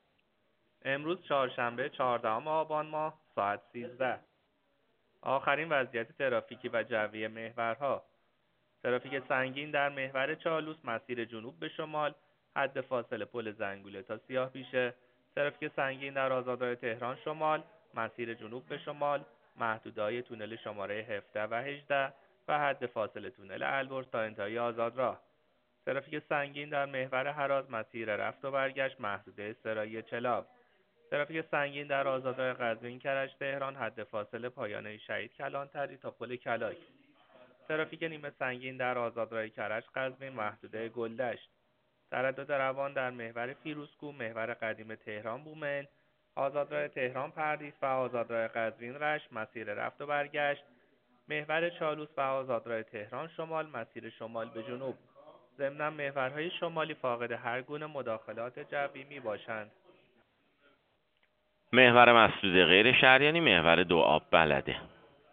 گزارش رادیو اینترنتی از آخرین وضعیت ترافیکی جاده‌ها ساعت ۱۳ چهاردهم آبان؛